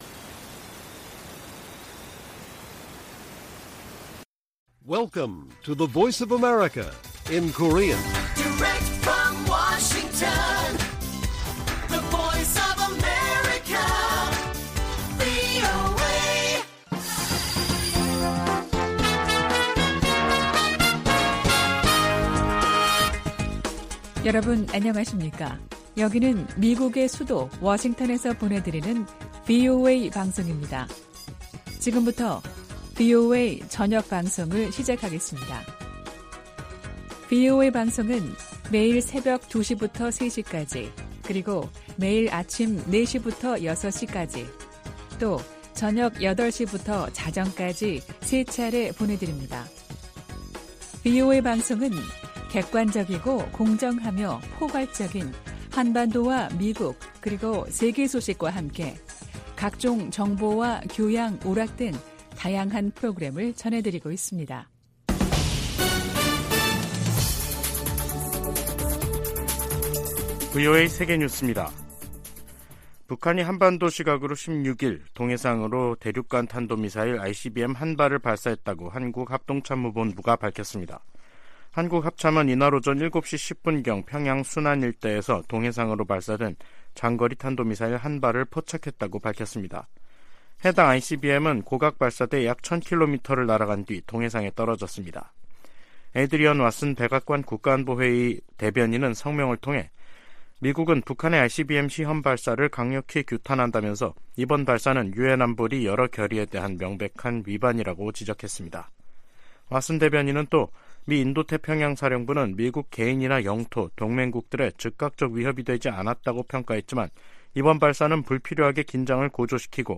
VOA 한국어 간판 뉴스 프로그램 '뉴스 투데이', 2023년 3월 16일 1부 방송입니다. 윤석열 한국 대통령과 기시다 후미오 일본 총리가 정상회담을 통해 북핵과 미사일 위협에 대응한 공조를 강화하기로 했습니다. 북한이 16일 ‘화성-17형’으로 추정되는 대륙간탄도미사일(ICBM)을 발사했습니다. 미국 정부는 북한의 ICBM 발사가 안보리 결의에 위배되고 역내 긴장을 고조시킨다며 강력 규탄했습니다.